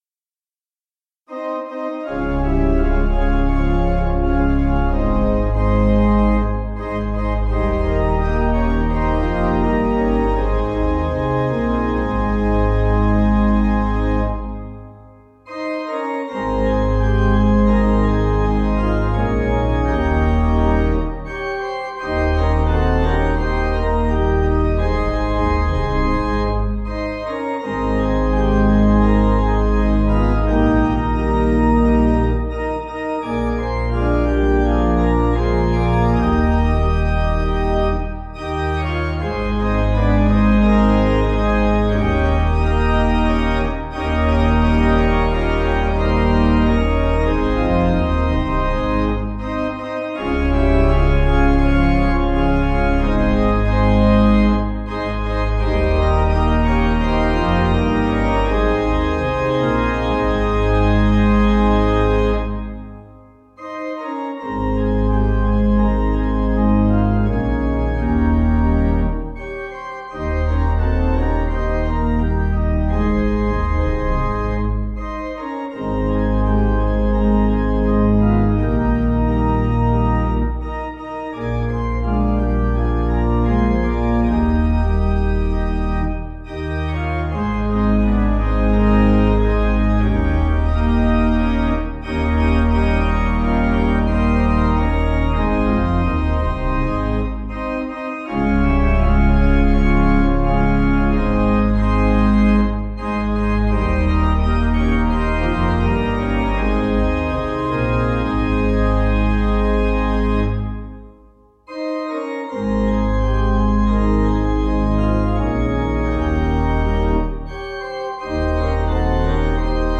Organ
(CM)   4/Ab 490.7kb